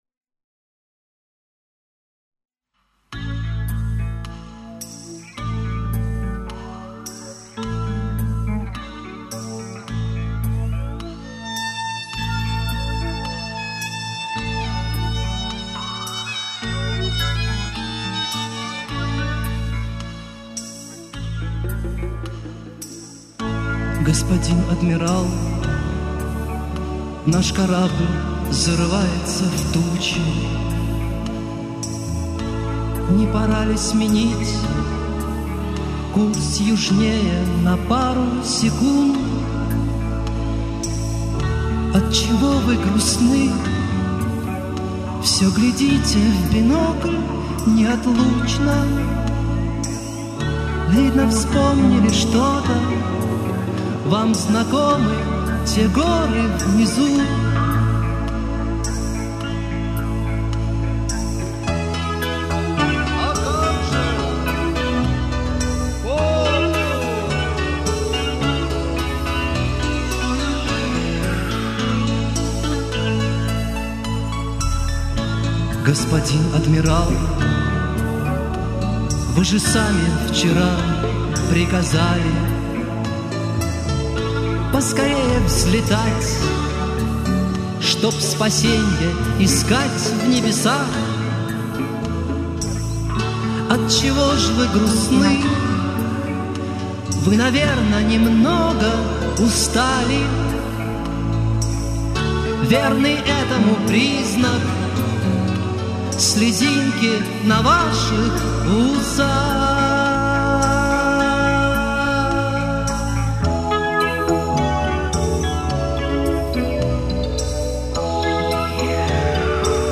Made in Monday-City (Dushanbe).